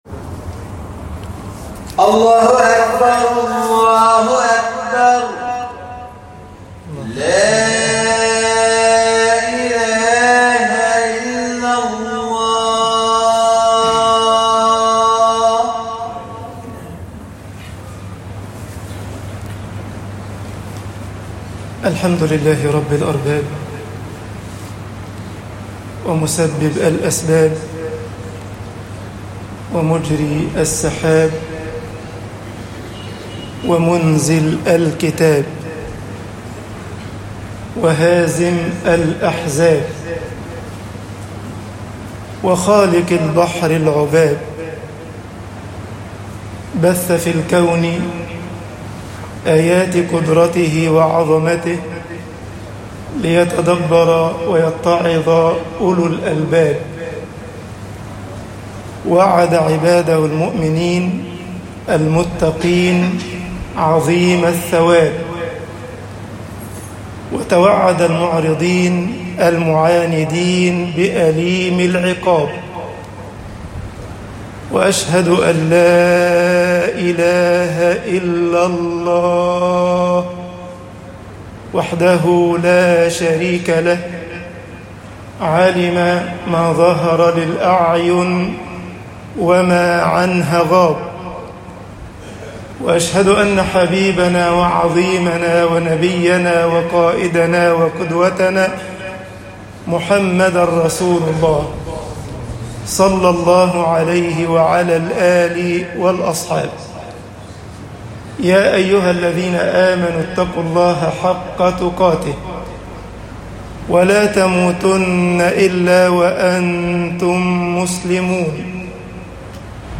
خطب الجمعة - مصر طوبى وويل طباعة البريد الإلكتروني التفاصيل كتب بواسطة